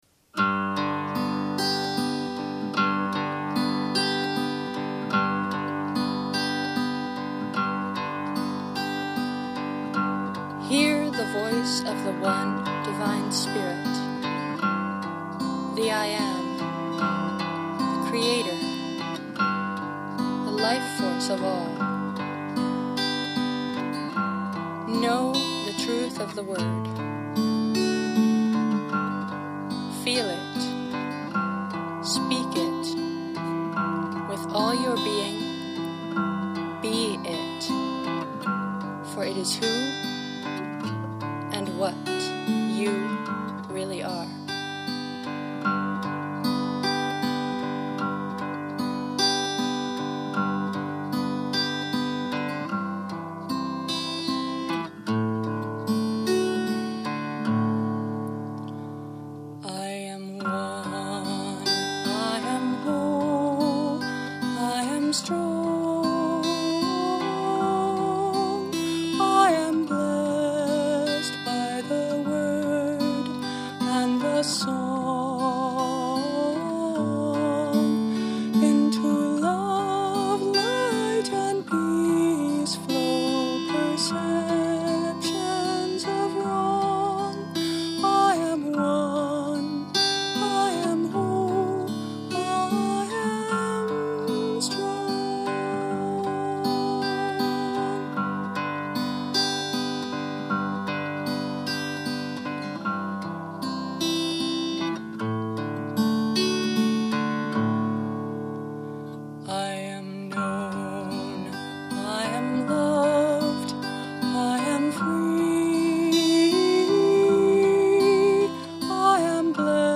Whole – Spiritual Song
Instrument: Tempo – Seagull Excursion Folk Acoustic Guitar
(Tenor guitar tuning, GDAE)